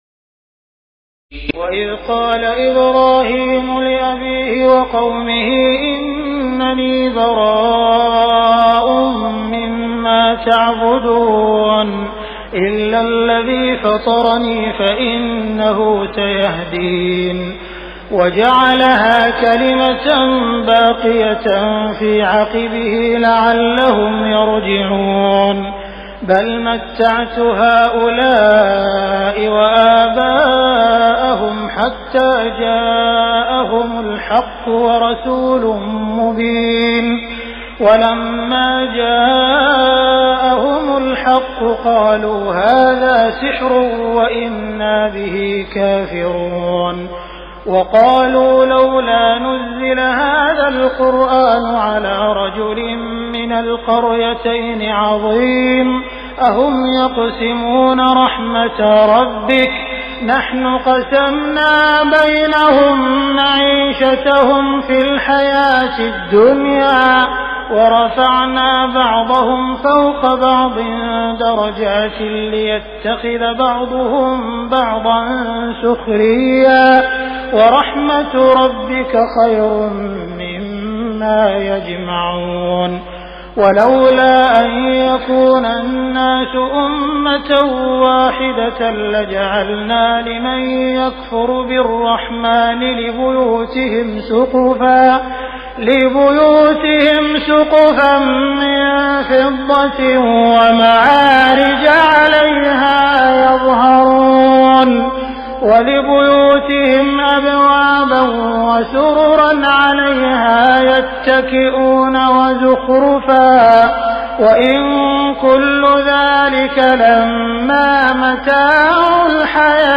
تراويح ليلة 24 رمضان 1418هـ من سور الزخرف (26-89) والدخان و الجاثية Taraweeh 24 st night Ramadan 1418H from Surah Az-Zukhruf and Ad-Dukhaan and Al-Jaathiya > تراويح الحرم المكي عام 1418 🕋 > التراويح - تلاوات الحرمين